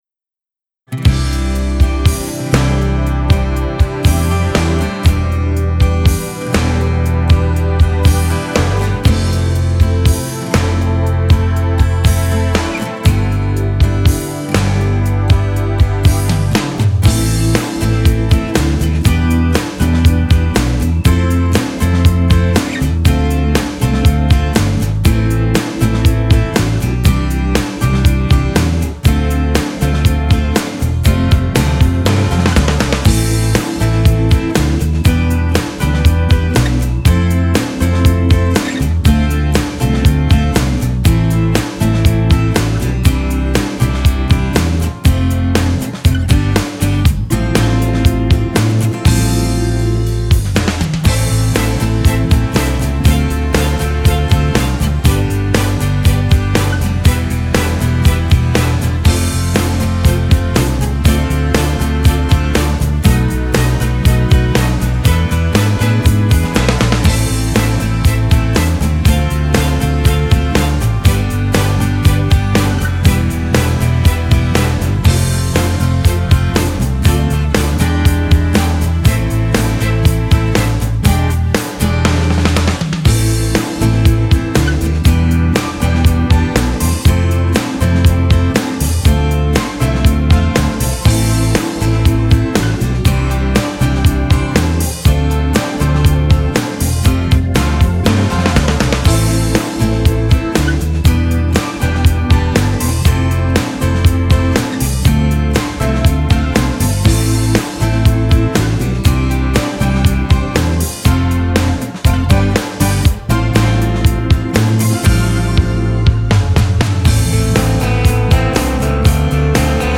Прослушать фонограмму